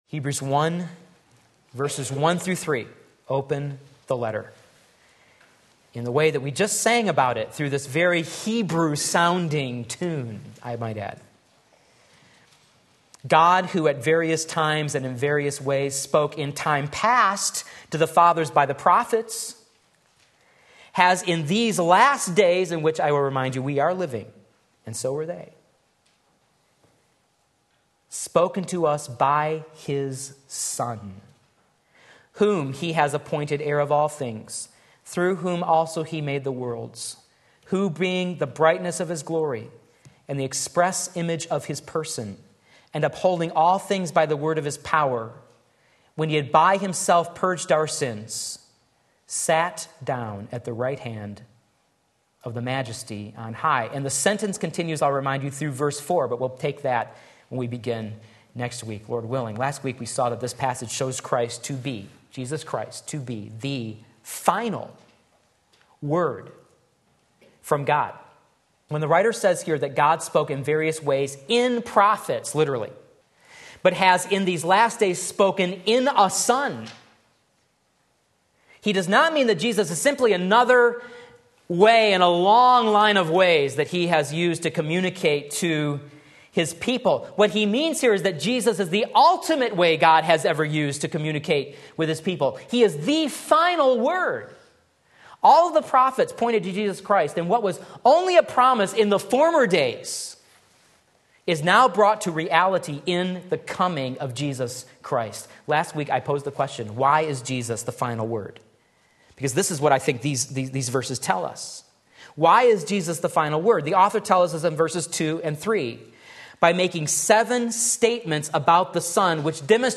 Sermon Link
Part 2 Hebrews 1:1-3 Sunday Morning Service